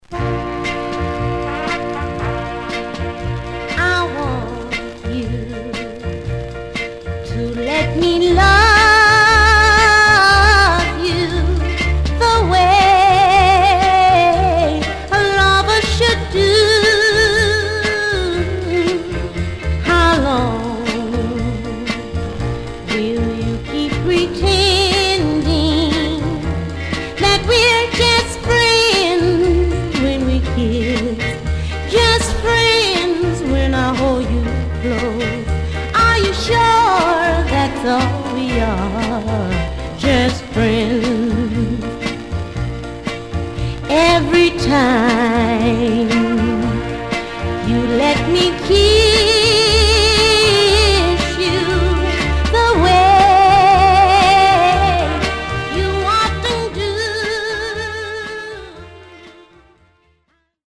Stereo/mono Mono
Soul